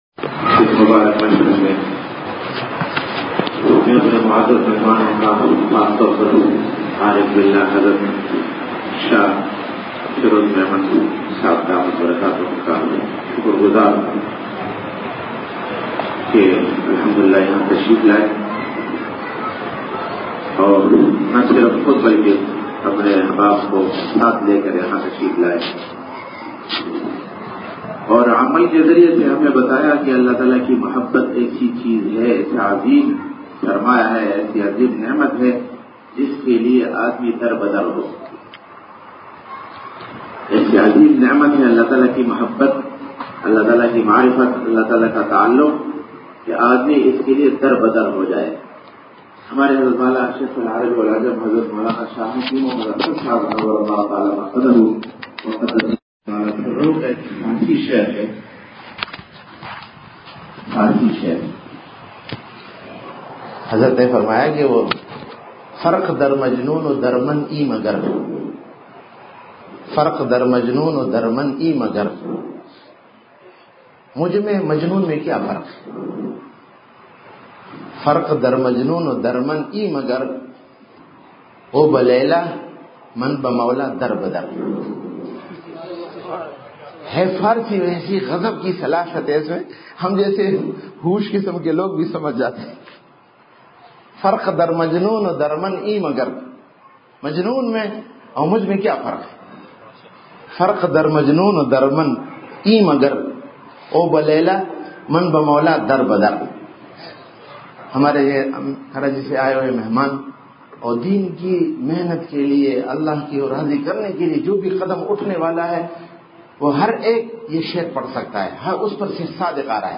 بمقام :۔جامع مسجد برھان خیل بڈھ بیر پشاور
بعدمغرب بیان
جذب سے متعلق حضرت والا رحمۃ اللہ علیہ کے اشعار ترنم اور جوش سے پڑھے۔